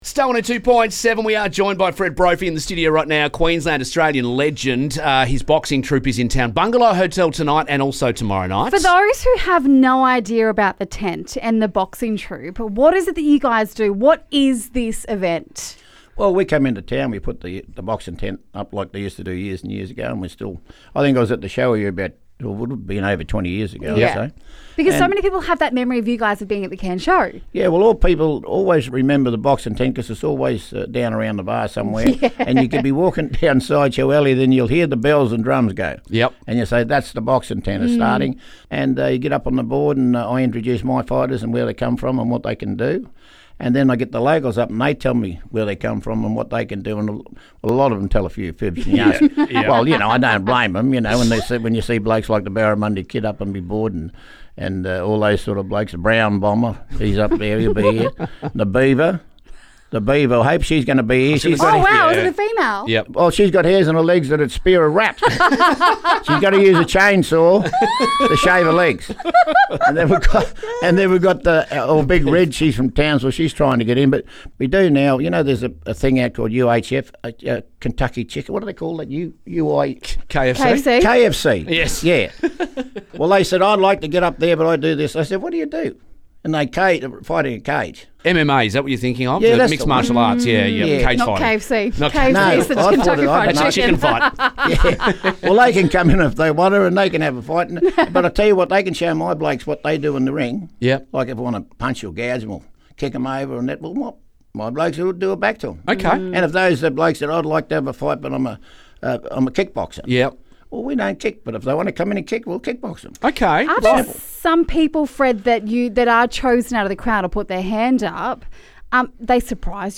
in the studio....